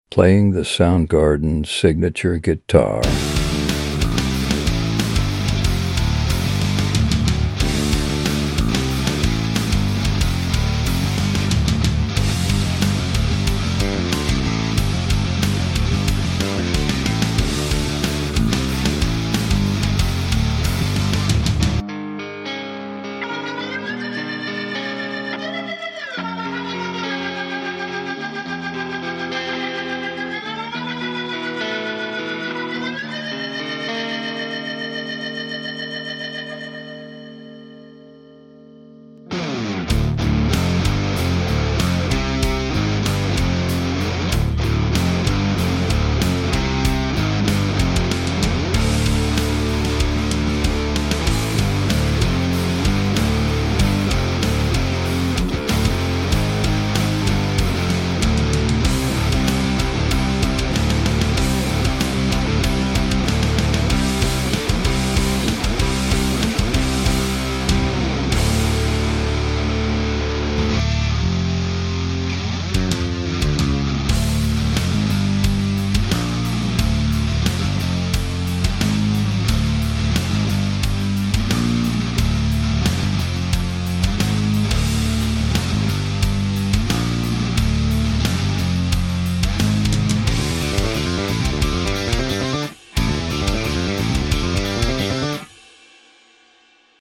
Kim Thayil Soundgarden signature guitar